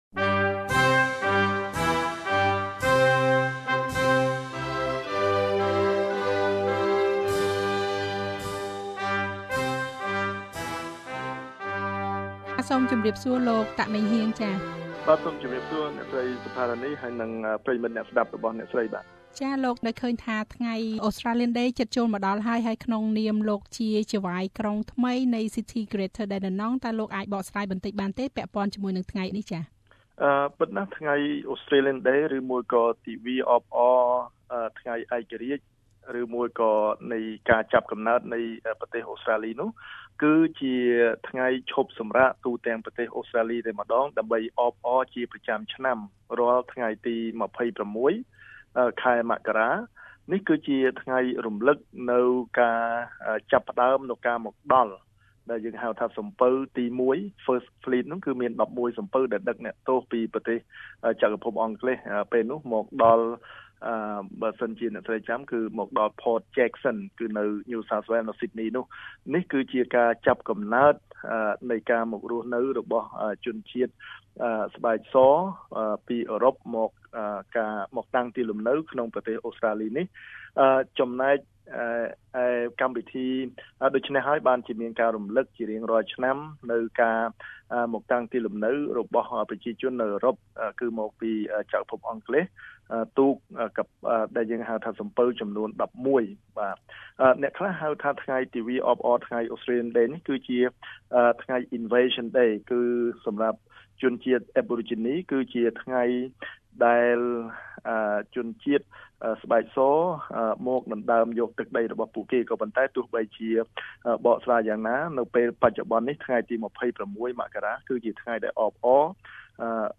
បទសម្ភាស ជាមួយនឹងលោកចៅហ្វាយក្រុងនៃ City of Greater Dandenong ដែលមានដើមកំណើតជាជនជាតិខ្មែរគឺ លោក តាក ម៉េងហៀង ស្តីពីអត្ថន័យថ្ងៃបុណ្យ Australia Day ថាតើមានន៏យយ៉ាងណាចំពោះរូបលោក?